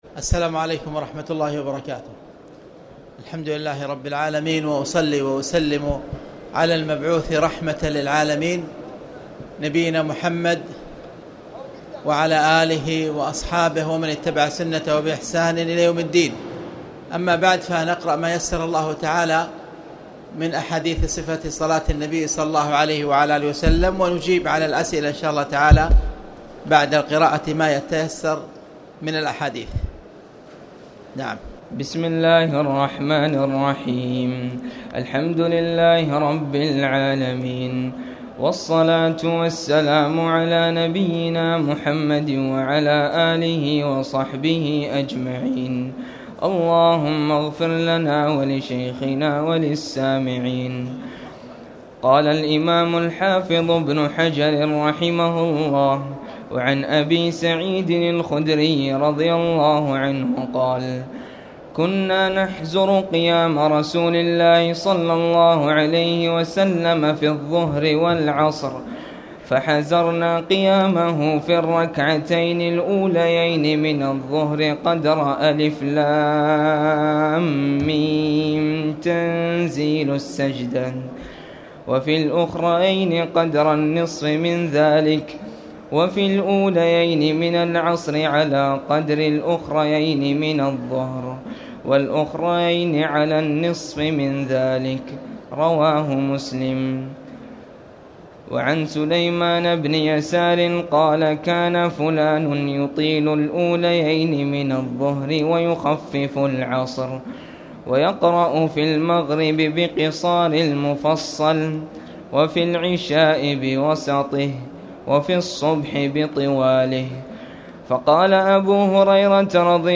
تاريخ النشر ١٣ رمضان ١٤٣٨ هـ المكان: المسجد الحرام الشيخ